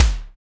WISPY KIK.wav